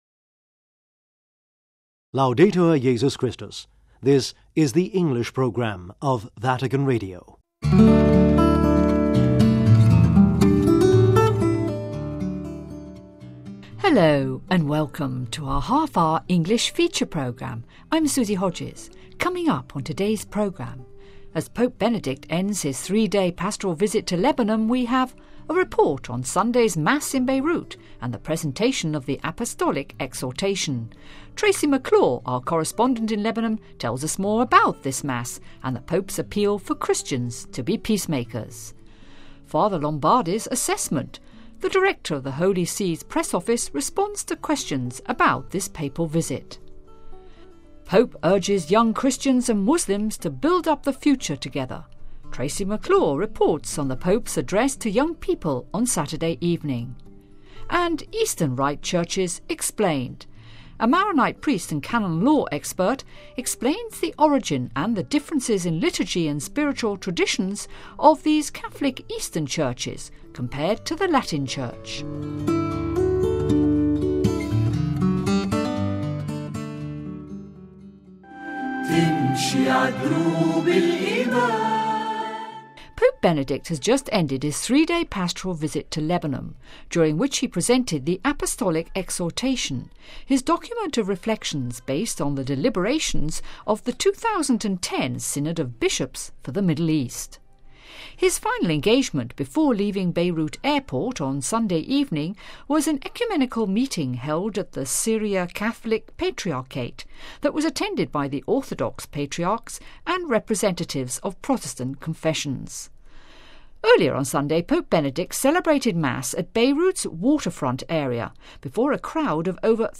EASTERN RITE CHURCHES EXPLAINED : A Maronite priest and Canon law expert explains the origin and the differences in liturgy and spiritual traditions of these Catholic Eastern Churches compared to the Latin Church.....